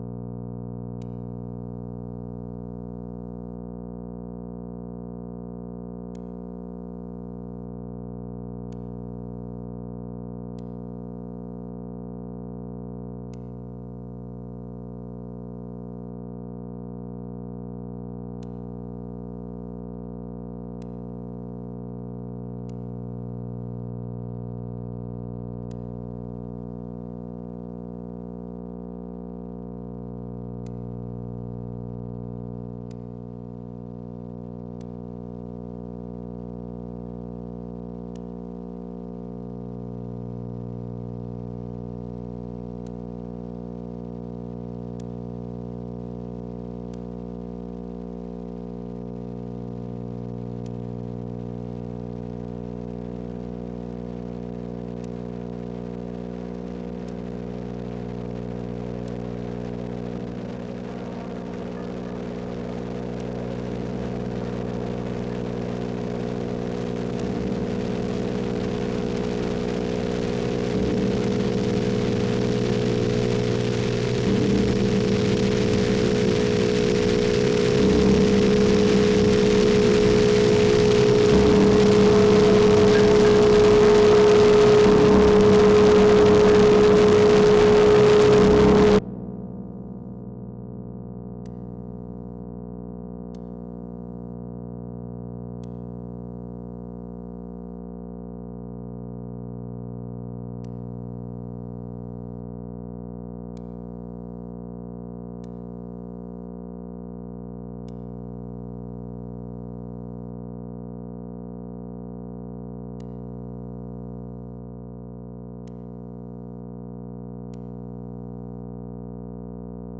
Music / Abstract
It seems you've caught the attention of something ( Please adjust your volume so that the first 30 seconds is quiet, but noticeable. Thanks! )